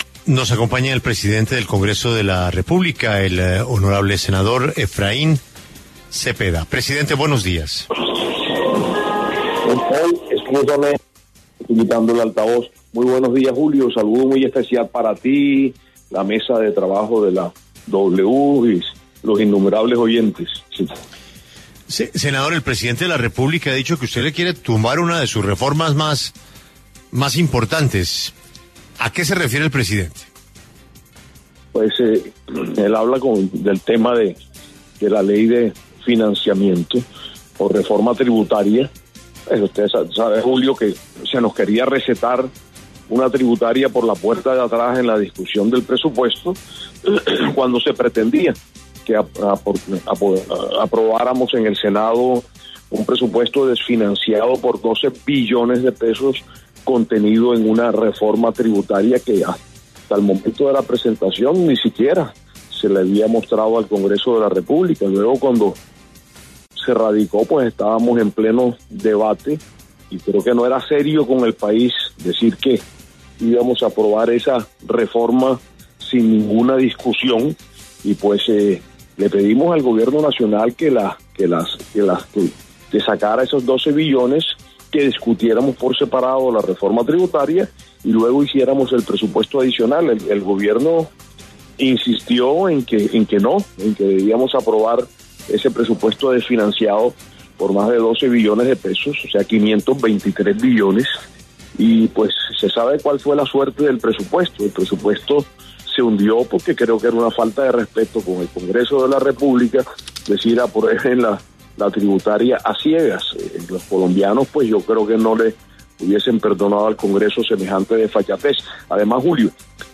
El presidente del Congreso, Efraín Cepeda, respondió en La W sobre los señalamientos del presidente Petro sobre trabas en el Legislativo a la ley de financiamiento.